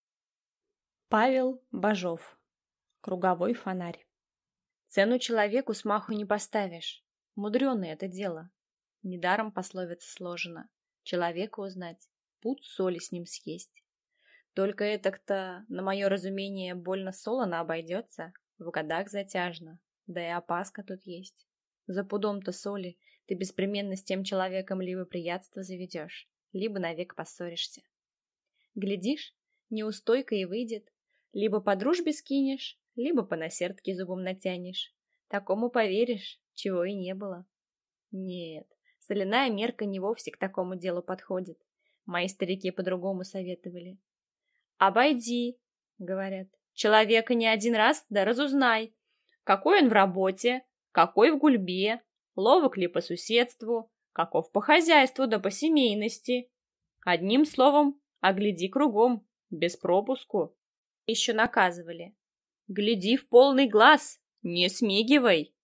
Аудиокнига Круговой фонарь | Библиотека аудиокниг